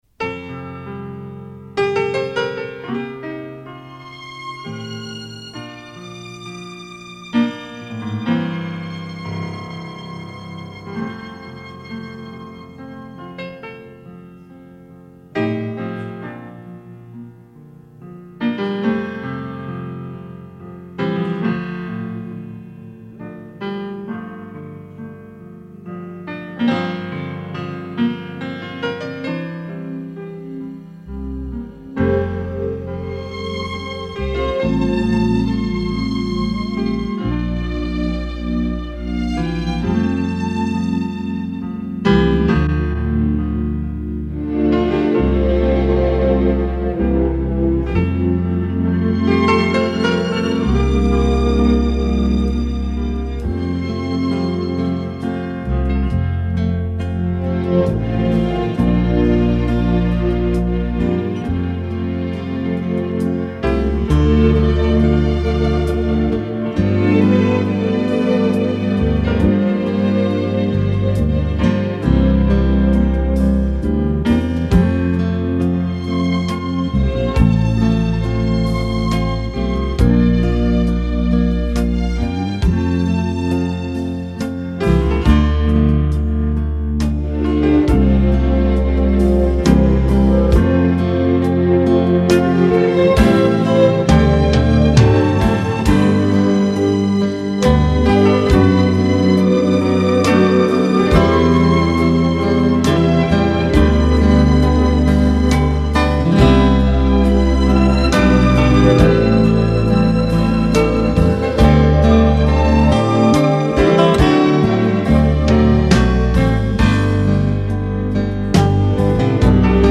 Playbacks-KARAOKE